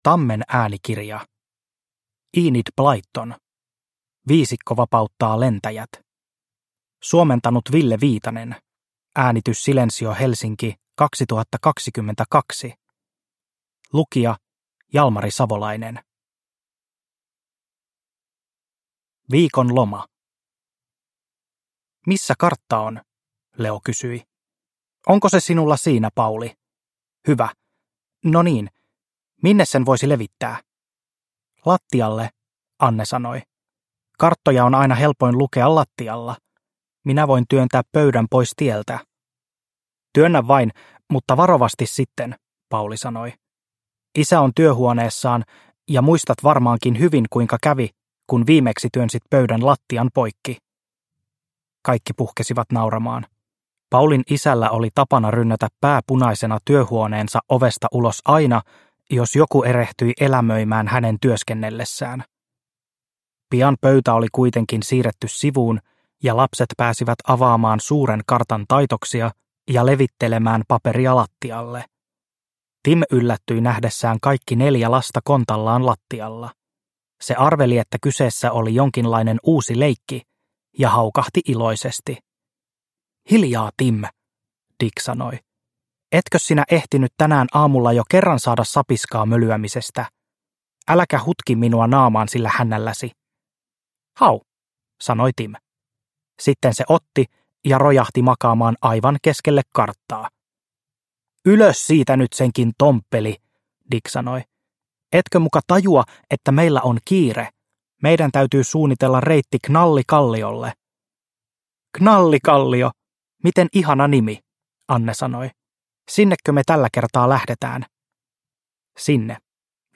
Viisikko vapauttaa lentäjät – Ljudbok – Laddas ner